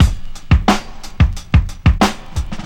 • 90 Bpm Drum Beat D Key.wav
Free drum loop - kick tuned to the D note. Loudest frequency: 675Hz
90-bpm-drum-beat-d-key-x0R.wav